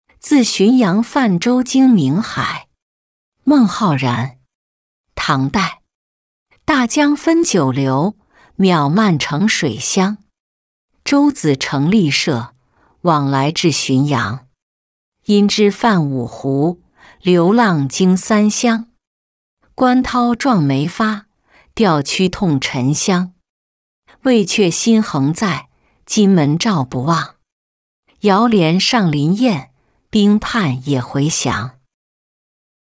自浔阳泛舟经明海-音频朗读